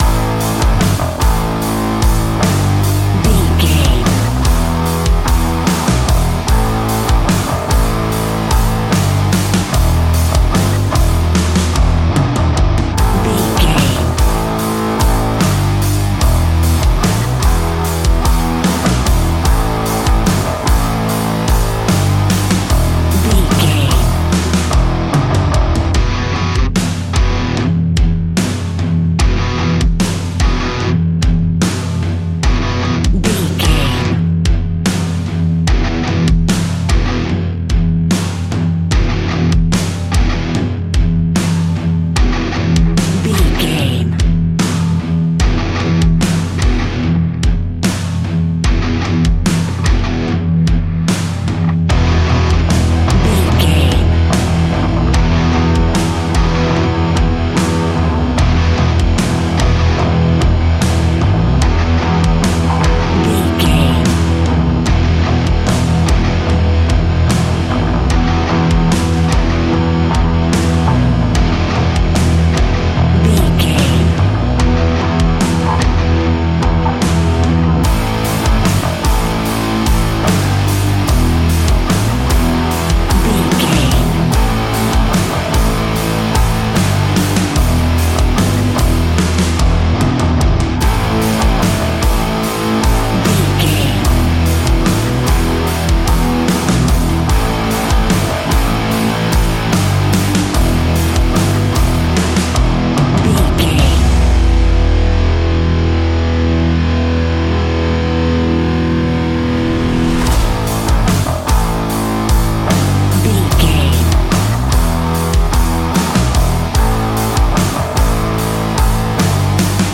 Ionian/Major
E♭
hard rock
guitars
instrumentals